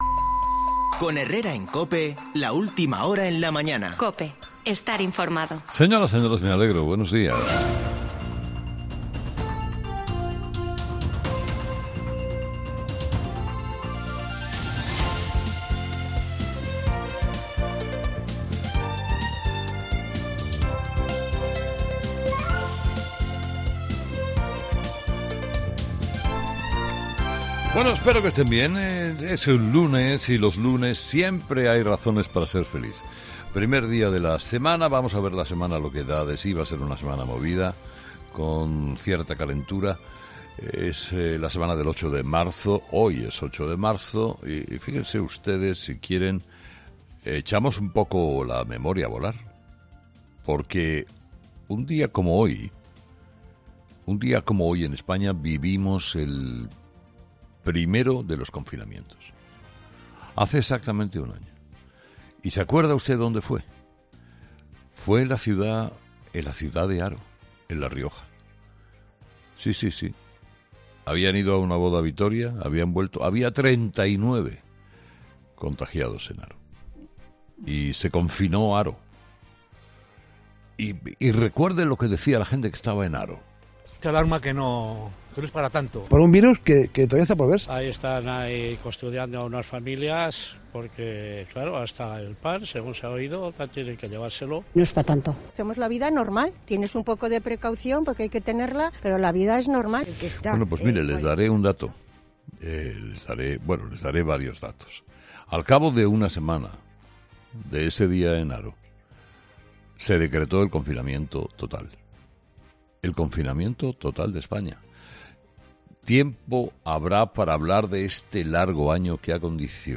EL 8M, DETONANTE DE LOS CONTAGIOS Sobre la pandemia también ha hablado Herrera en su monólogo de las 8 .